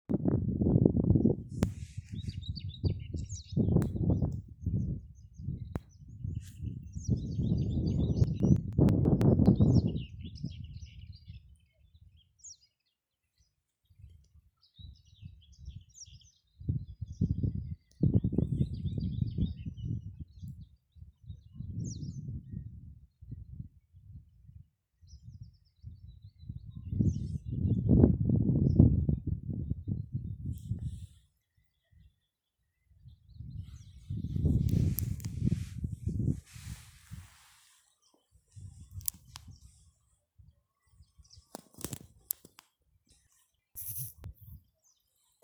Whitethroat, Curruca communis
StatusSinging male in breeding season
NotesViensēta ar lauku ainavu